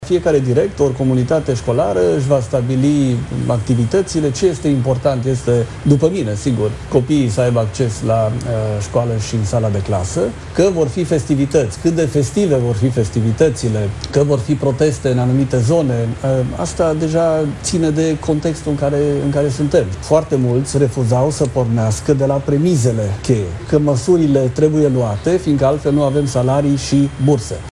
Ministrul Daniel David, într-un interviu la Prima News: „Încercăm să ușurăm activitatea celorlalți, chiar dacă vorbim de 1,4%, fiecare om contează”